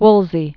(wlzē), Cardinal Thomas 1475?-1530.